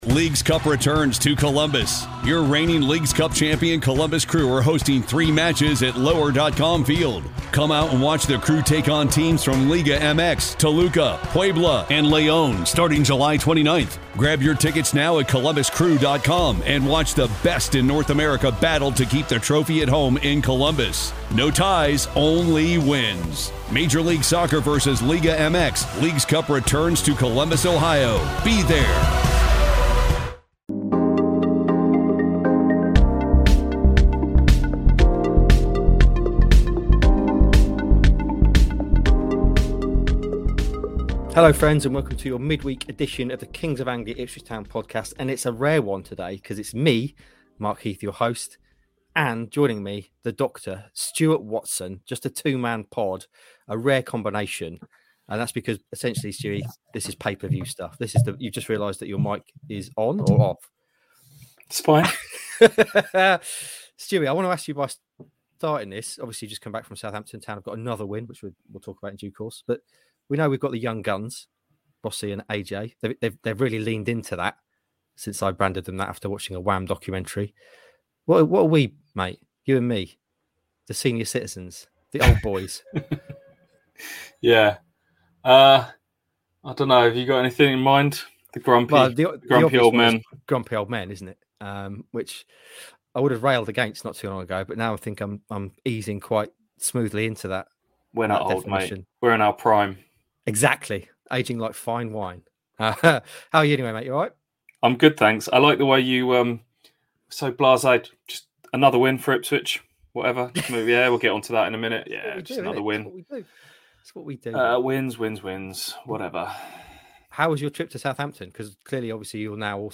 a rare two-man pod in the wake of Town’s latest win at Southampton.